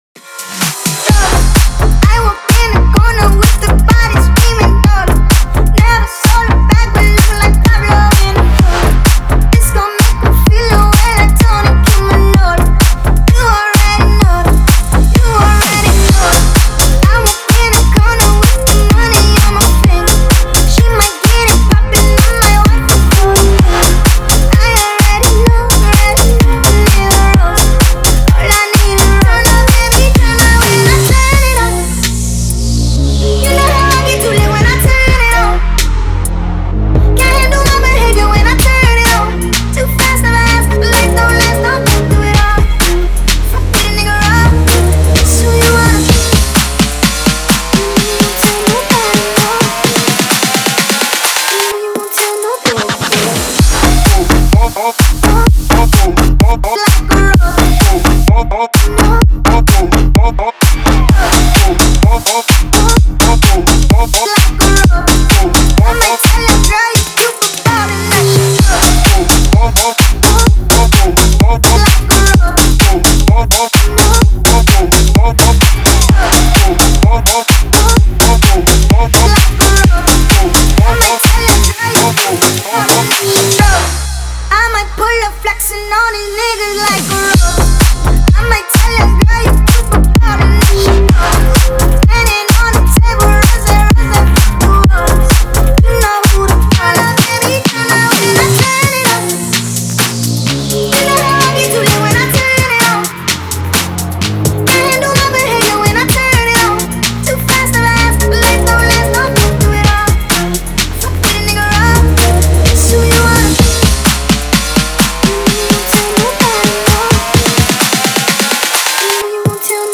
это яркая и запоминающаяся композиция в жанре хип-хоп и R&B